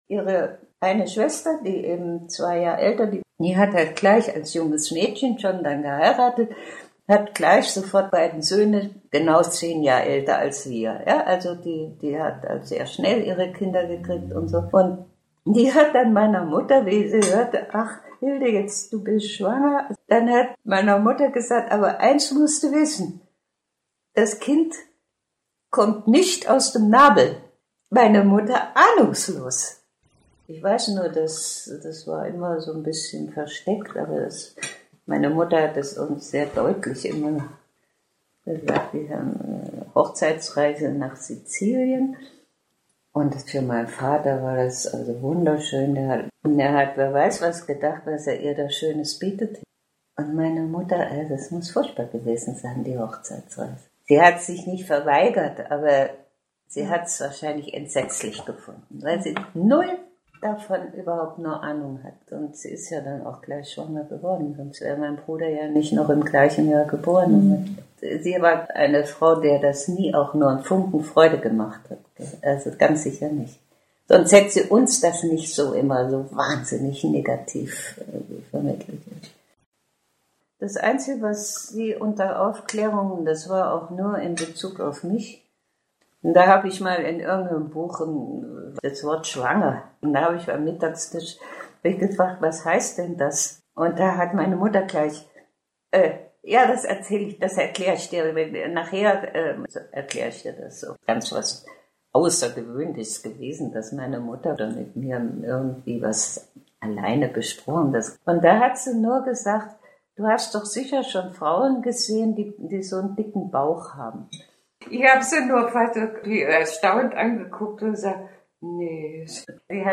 Diesen Fragen widmen sich die Interviews dreier Frauen der Jahrgänge 1935, 1943 und 1955: So werden beispielweise Frauenarzt/-ärztinnenbesuche, Erfahrungen mit Abtreibung, Auseinandersetzungen bezüglich Sexualmoral, aber auch gesellschaftliche Umbrüche in ihren Erzählungen geschildert.
Die Interviewausschnitte sind absteigend nach Alter zusammengeschnitten.